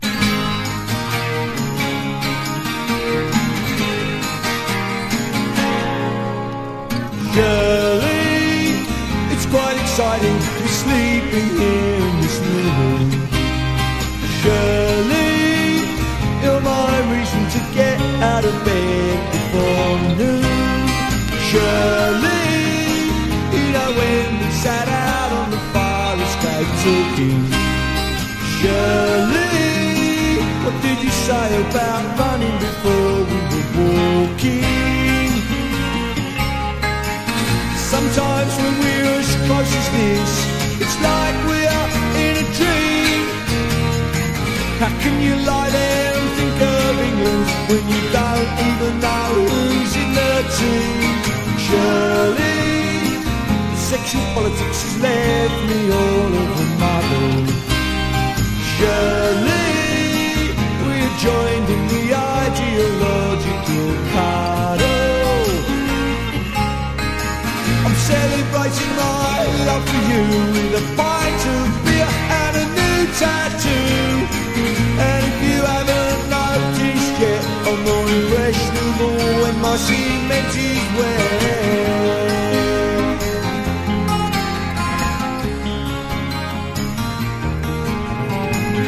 SSW / FOLK# PUNK / HARDCORE